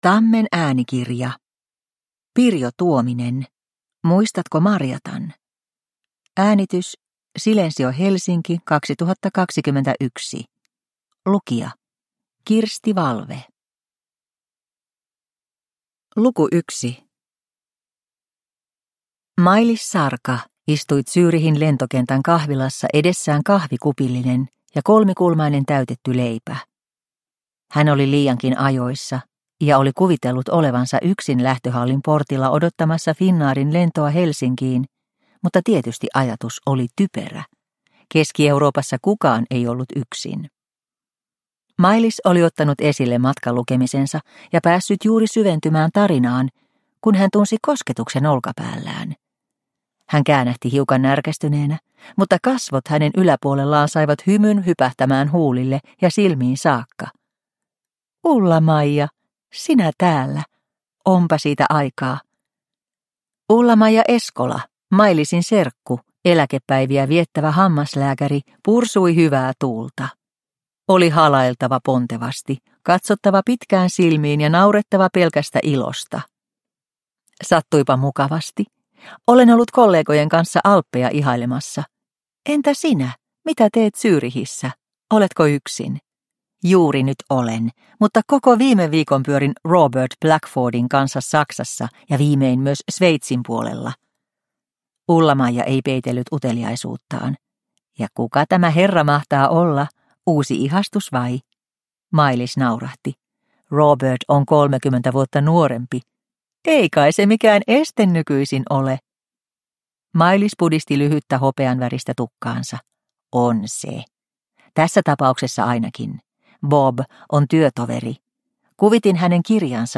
Muistatko Marjatan? – Ljudbok – Laddas ner